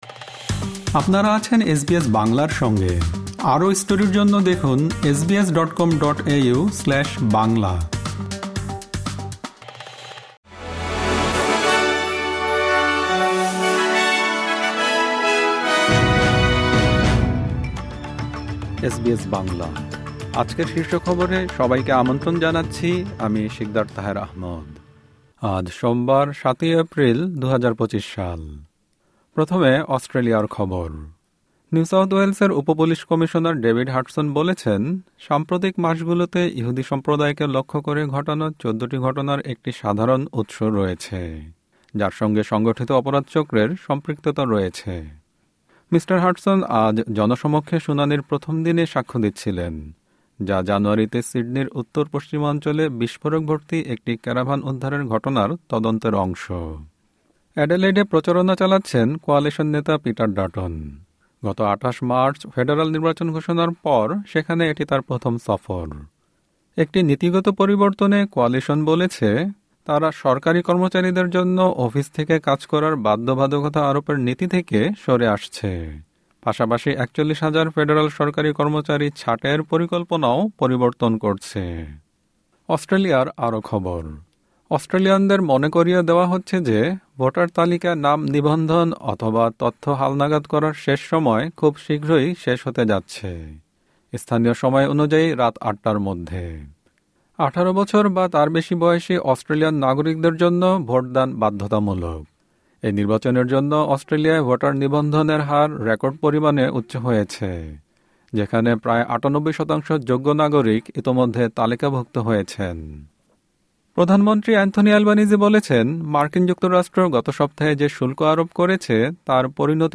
এসবিএস বাংলা শীর্ষ খবর: ৭ এপ্রিল, ২০২৫